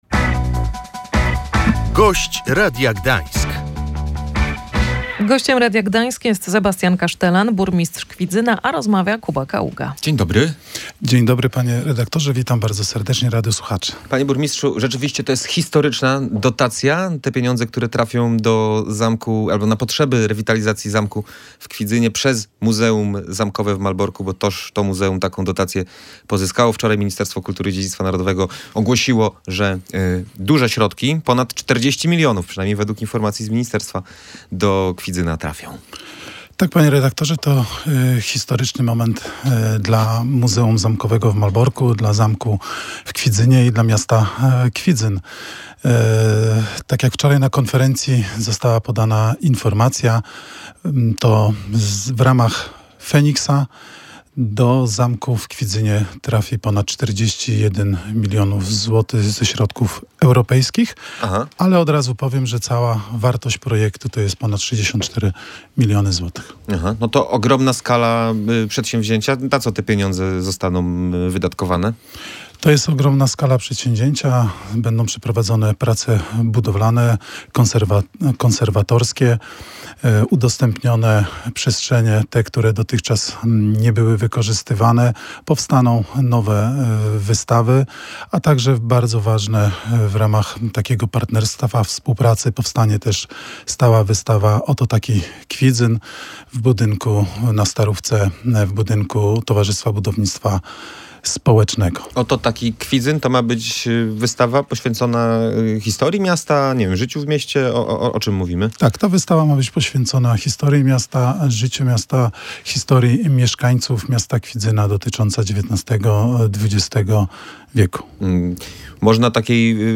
To przede wszystkim sukces Muzeum Zamkowego w Malborku i pracowników zamku w Kwidzynie, ale także ogromna szansa dla miasta – tak o dotacji na prace konserwatorskie średniowiecznego zamku mówił w audycji „Gość Radia Gdańsk” Sebastian Kasztelan, burmistrz Kwidzyna.